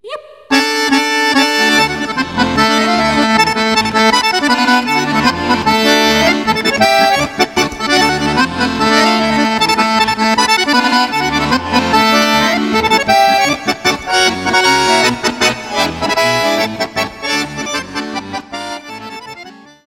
harmonikka accordion